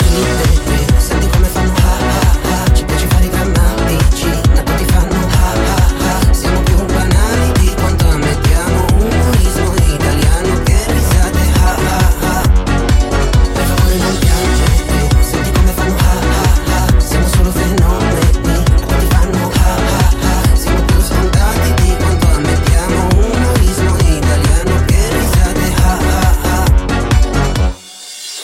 Genere: ,pop,trap,dance,rap,remix,italiana,hit